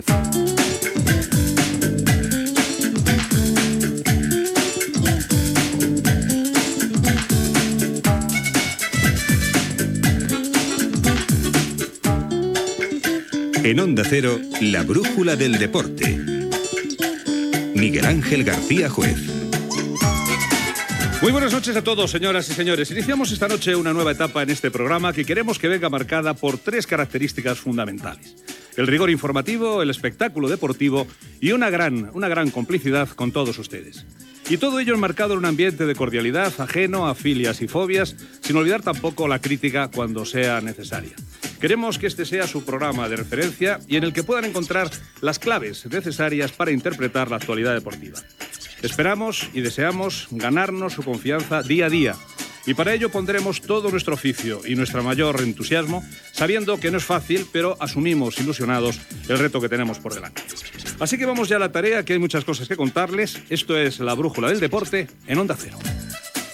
Careta del programa, objectius i estil.